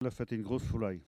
Elle provient de Sallertaine.
Catégorie Locution ( parler, expression, langue,... )